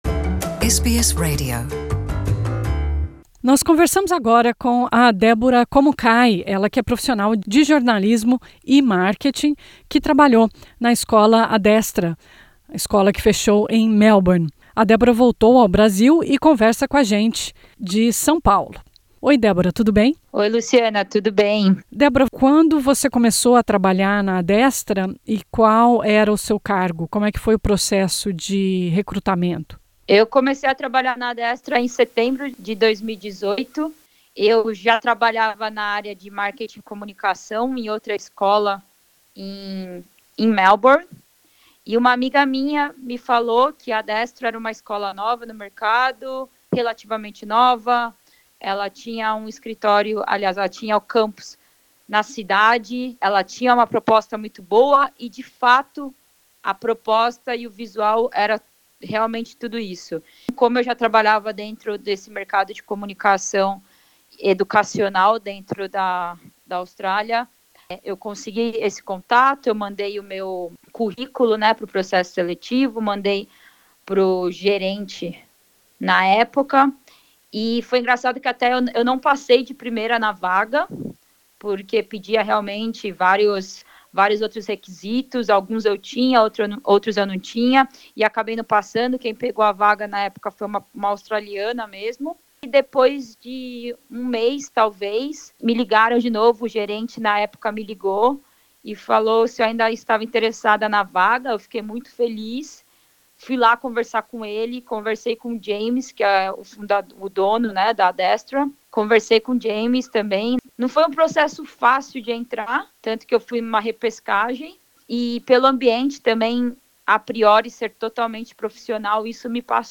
Ela falou à SBS, de São Paulo.